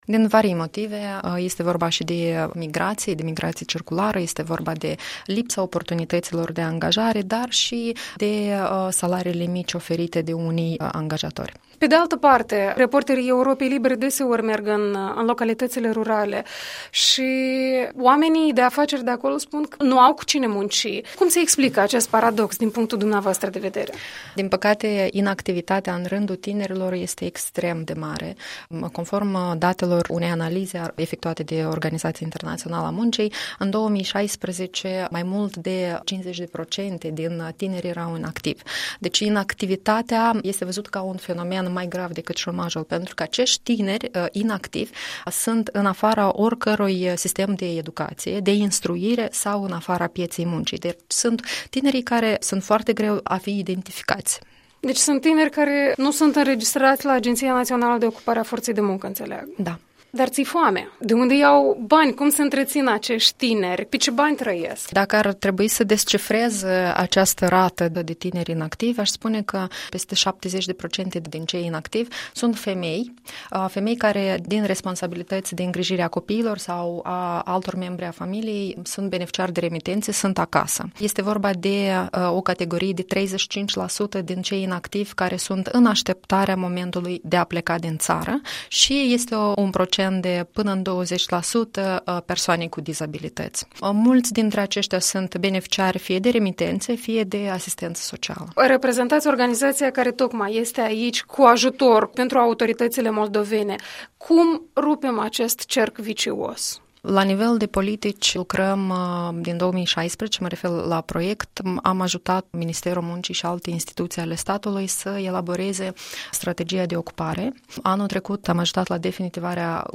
Datele Organizației Internaționale a Muncii (OIM) arată că în Republica Moldova tinerii sunt de aproape trei ori mai susceptibili de a deveni șomeri. Cum se explică aceste cifre și cum pot fi sprijiniți cei care, de fapt, reprezintă pătura cea mai activă a societății? Un interviul la temă cu...